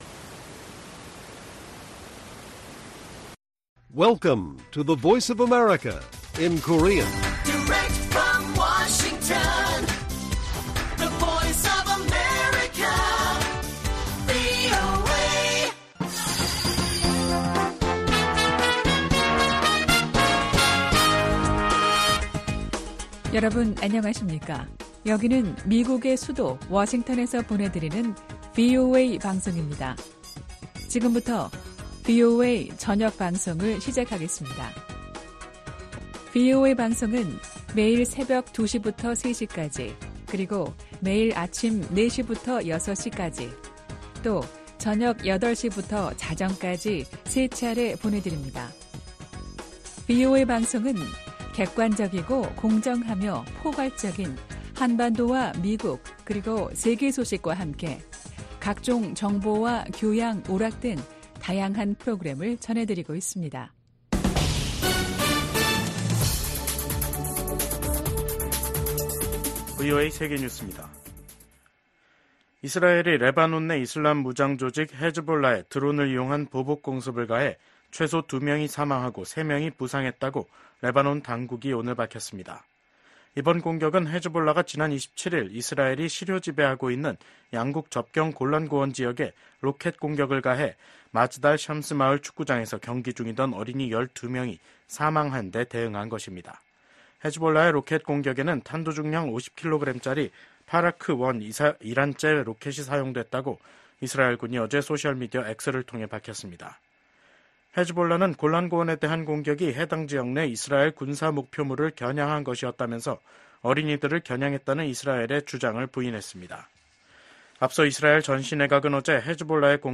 VOA 한국어 간판 뉴스 프로그램 '뉴스 투데이', 2024년 7월 29일 1부 방송입니다. 미국과 한국, 일본이 안보 협력을 제도화하는 문서에 서명했습니다.